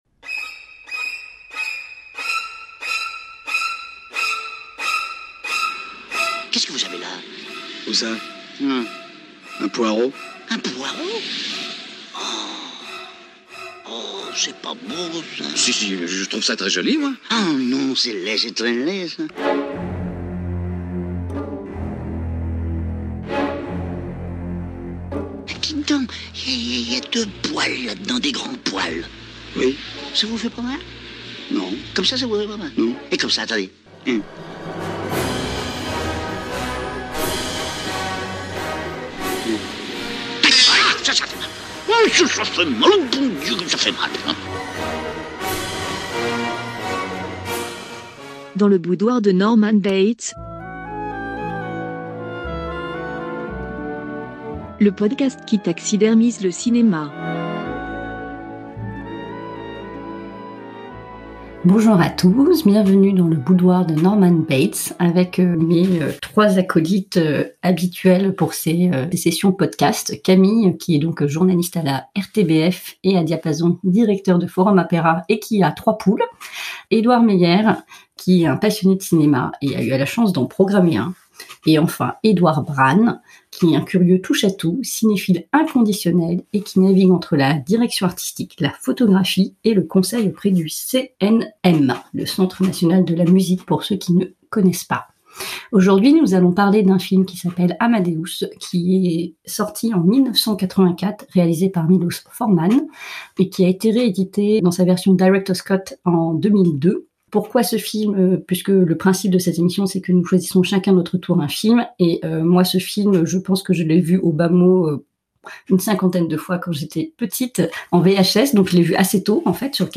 Le revoir aujourd’ui est un choc ; car notre société a évolué, voilà pourquoi nous le soumettons au débat de quatre spécilistes du cinéma, attachés à la rédaction de Forumopera.